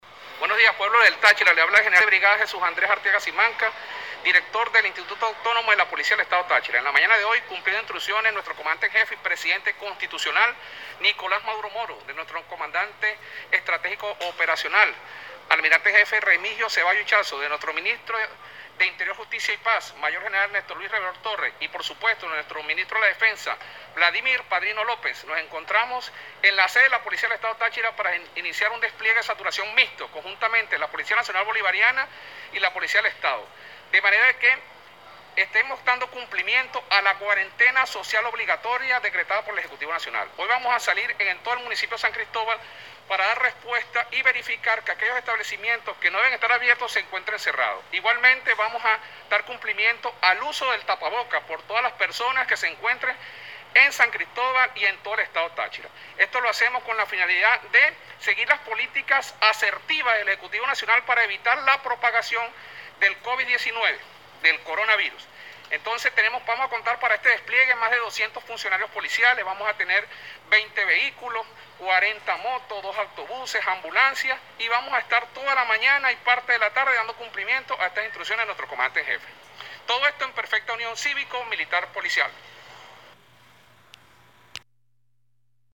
El director de PoliTáchira general de brigada Jesús Andrés Arteaga Simancas anunció vía audio de whatsapp que iniciaron un despliegue de saturación mixto con la policía nacional y la policía del estado.